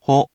We’re going to show you the character, then you you can click the play button to hear QUIZBO™ sound it out for you.
In romaji, 「ほ」 is transliterated as 「ho」which sounds like 「hohh